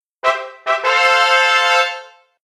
trumpet_announcement.ogg